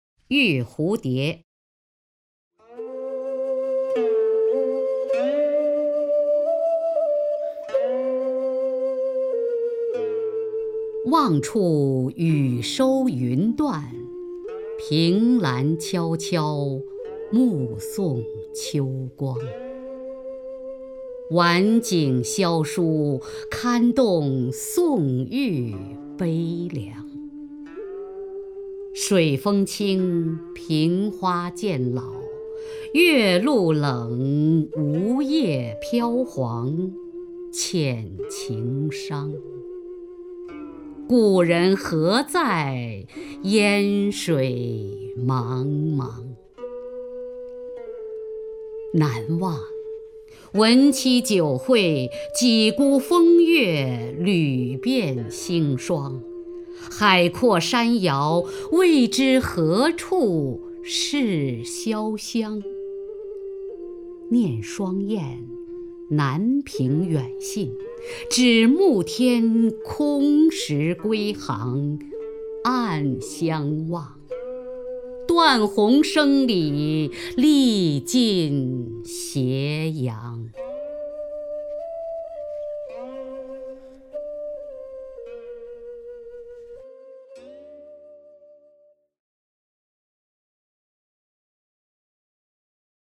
雅坤朗诵：《玉蝴蝶·望处雨收云断》(（北宋）柳永)
名家朗诵欣赏 雅坤 目录